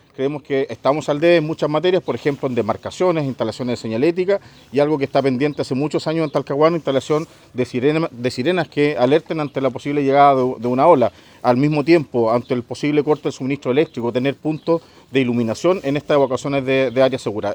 El alcalde de Talcahuano, Eduardo Saavedra, afirmó que en la comuna todavía está al debe en infraestructura y dispositivos sonoros.
alcalde-talcahuano.mp3